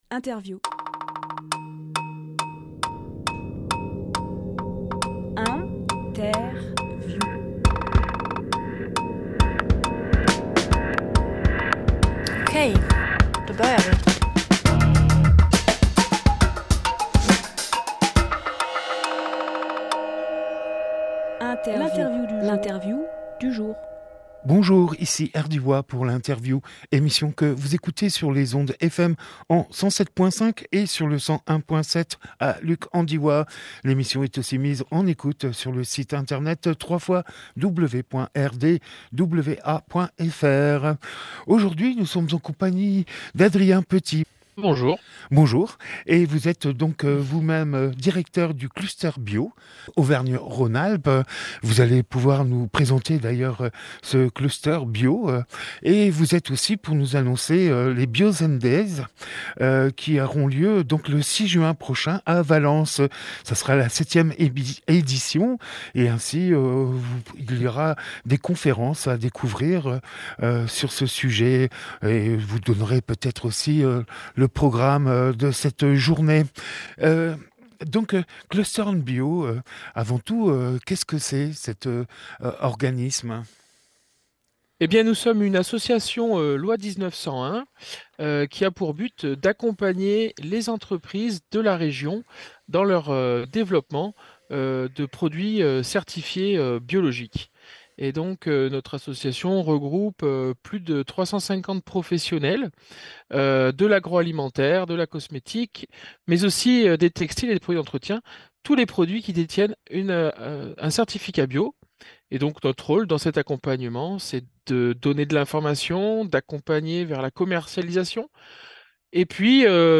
Emission - Interview Bio N’Days Publié le 27 avril 2023 Partager sur…
26.04.23 Lieu : Studio RDWA Durée